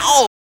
4504L SHOUT.wav